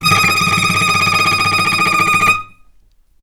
vc_trm-D#6-mf.aif